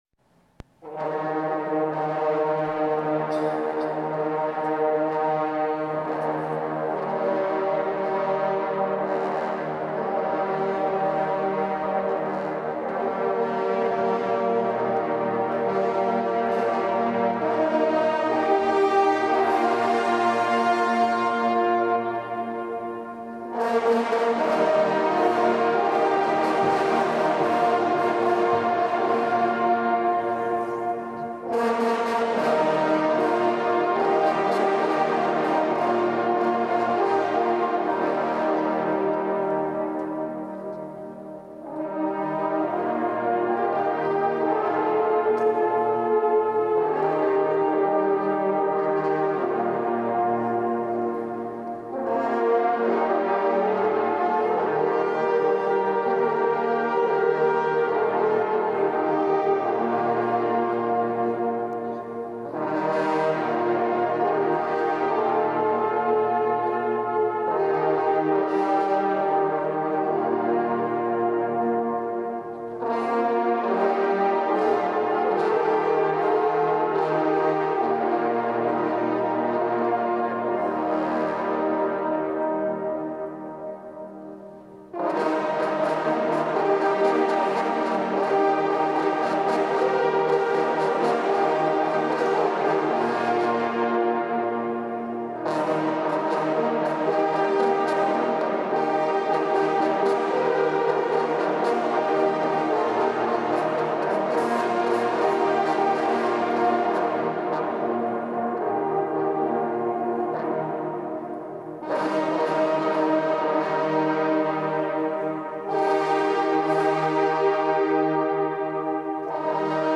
auf der Hompage der Parforcehornbläsergruppe:
VID-2025+Dom+Speyer++Introduction.mp4